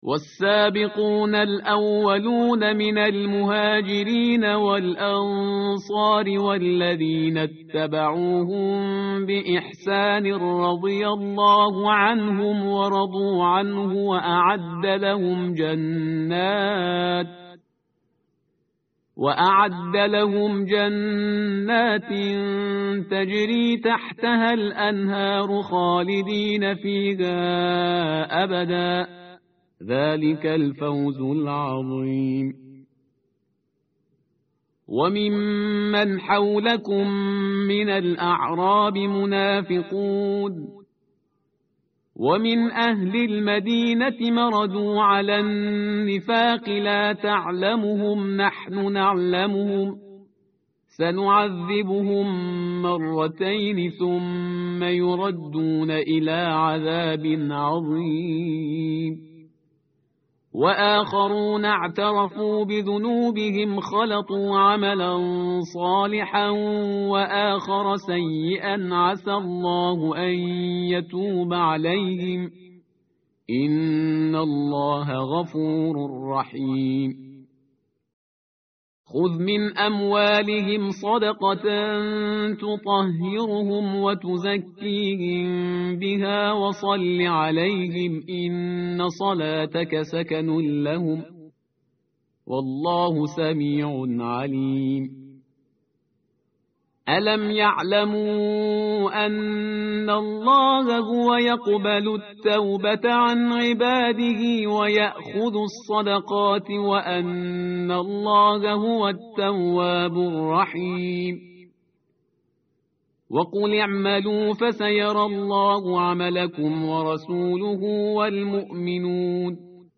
tartil_parhizgar_page_203.mp3